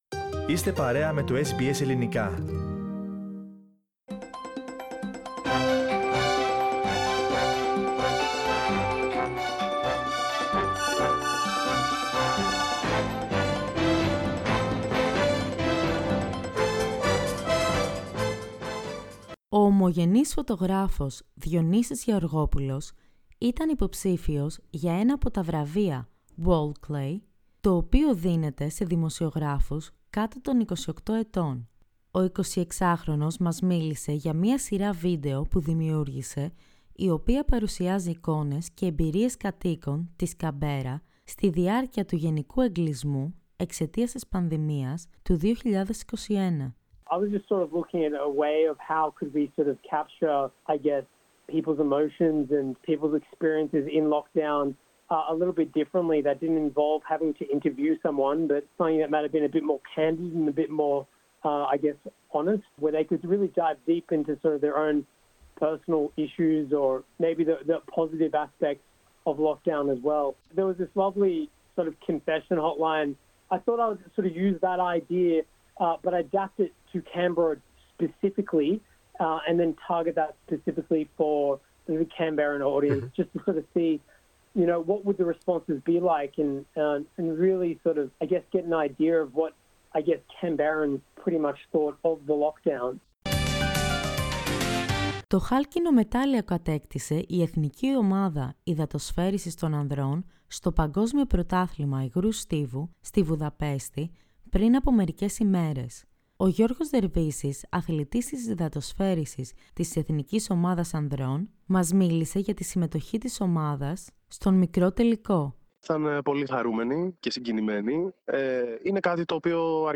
O ομογενής φωτογράφος που ήταν υποψήφιος για βραβείο δημοσιογραφίας, ο αθλητής της υδατοσφαίρισης της Εθνικής ομάδας Ανδρών, οΕλληνοαυστραλός αρχιτέκτονας που βραβεύτηκε και δύο Έλληνες Πανεπιστημιακοί που μιλούν για την διασπορά, ήταν μερικά από τα πρόσωπα που μας μίλησαν την περασμένη εβδομάδα.